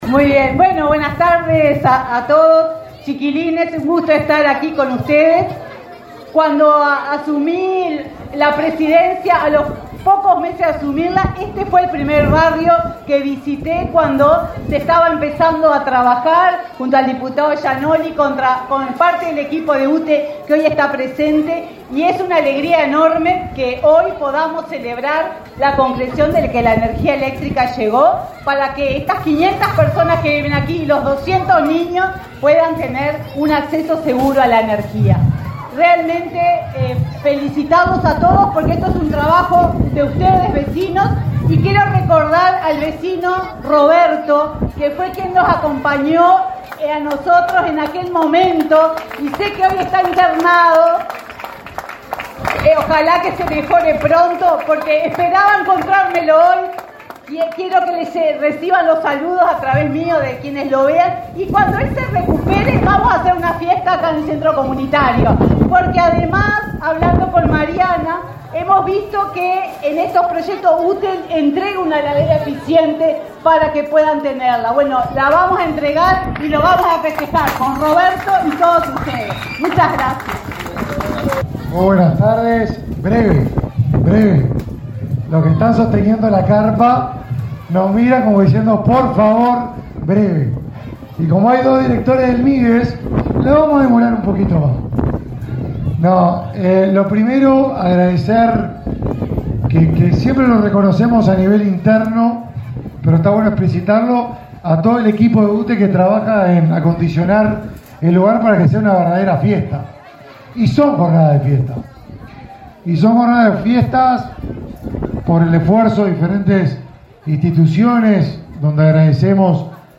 Conferencia de prensa por la inauguración de obras de electrificación en Montevideo
El Ministerio de Desarrollo Social (Mides) y la UTE inauguraron, este 29 de marzo, obras de electrificación, realizadas en el marco del Programa de Inclusión Social, que beneficia a 120 familias del barrio Unidos, de Montevideo. Participaron en el evento el titular del Mides, Martín Lema, y la presidenta de la UTE, Silvia Emaldi.